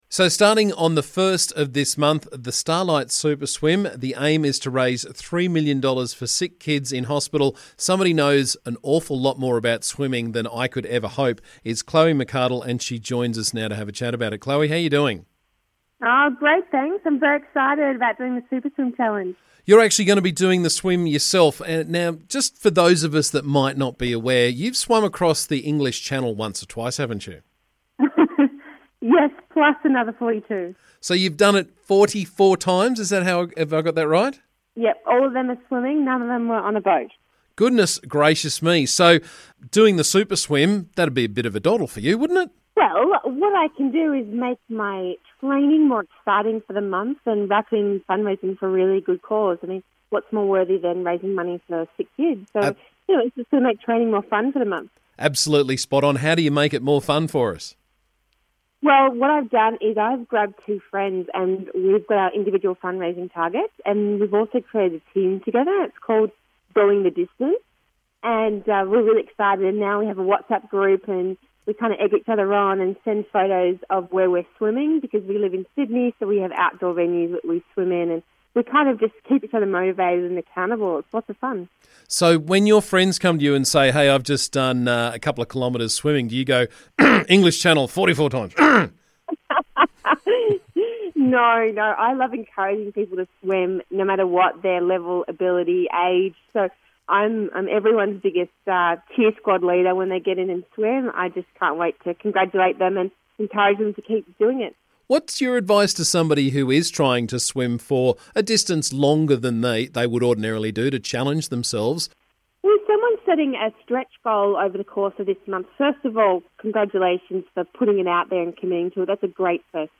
The Starlight Super Swim is on this month with the aim to raise $3 Million for sick kids. Chloe McCardel knows a thing or two about long distance swimming and challenging yourself so we had a chat on the show this morning.